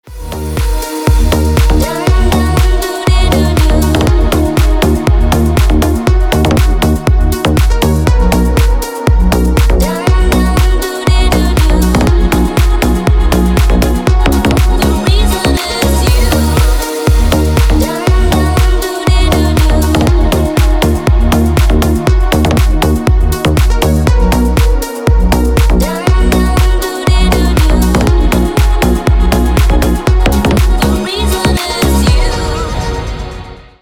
Deep House рингтоны